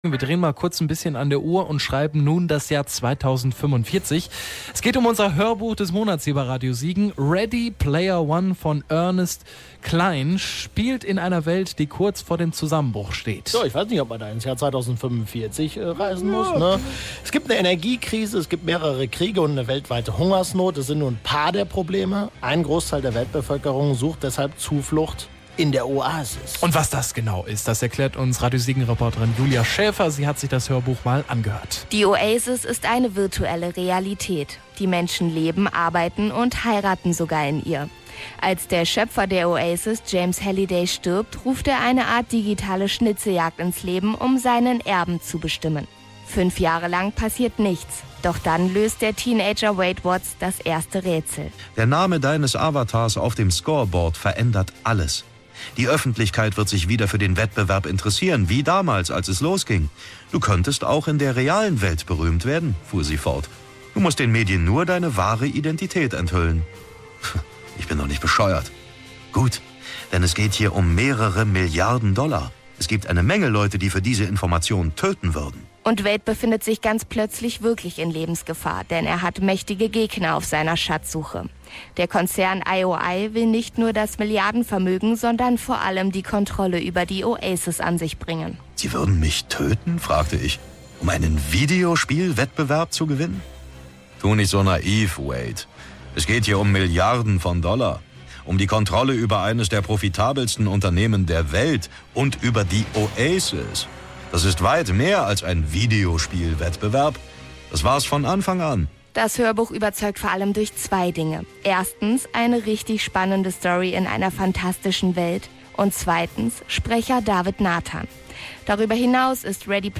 Eine phantastische Welt, eine spannende Geschichte und jede Menge Anspielungen auf die 80er-Jahre – „Ready Player One“ von Ernest Cline ist ein großartiges Science Fiction-Abenteuer, brillant gelesen von Sprecher David Nathan!